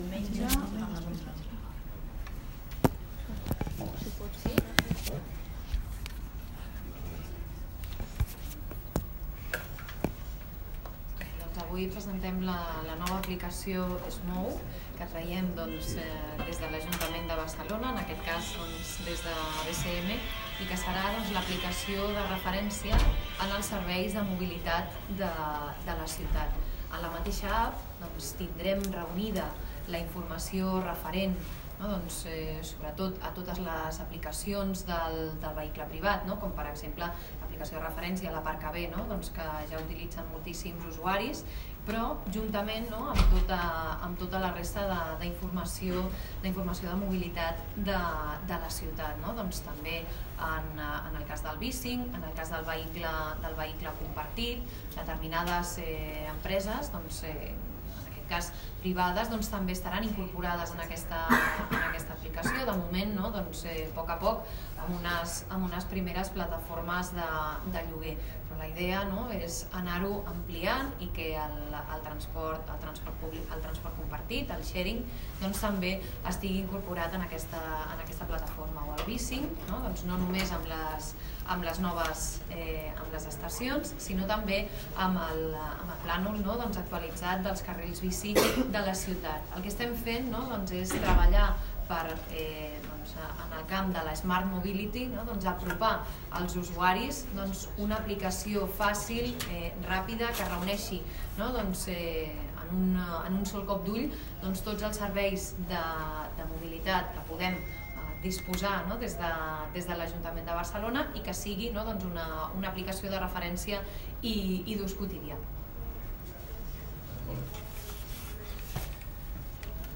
Declaracions Mercedes Vidal i Eloi Badia.